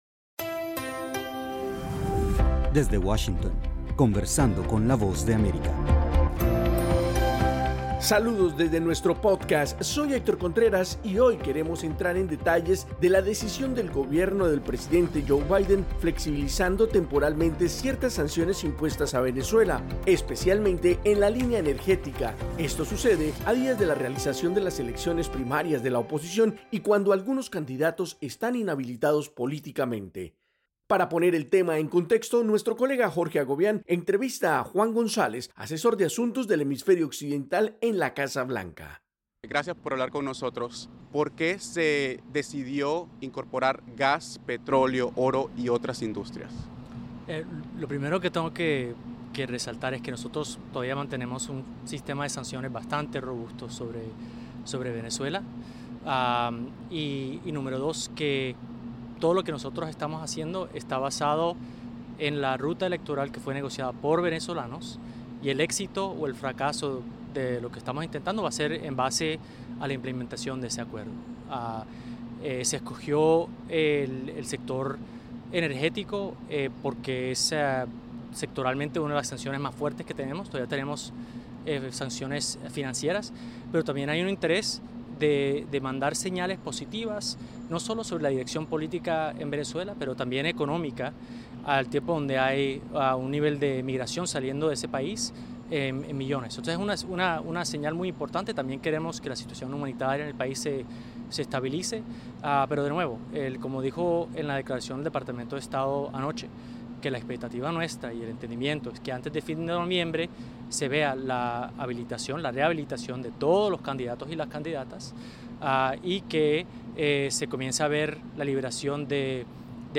entrevista a Juan Gonzalez, asesor en la Casa Blanca para Asuntos del Hemisferio Occidental